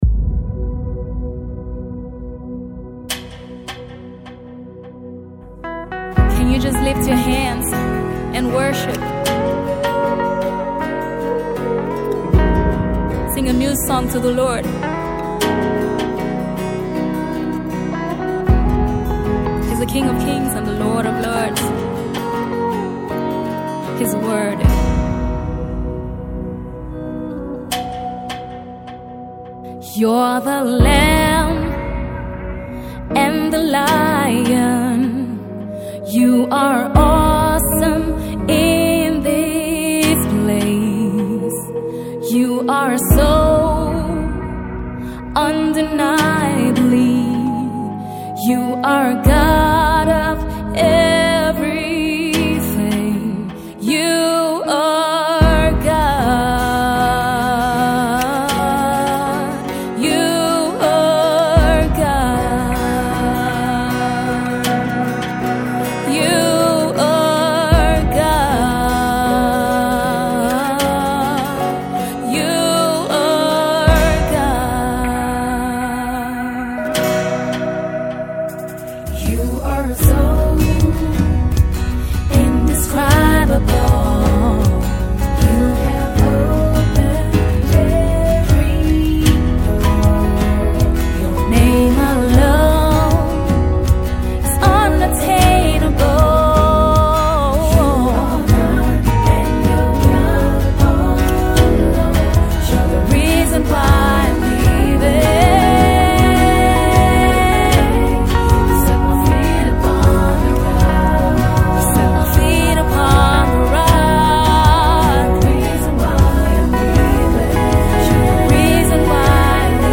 Gospel music
an unfeigned worship song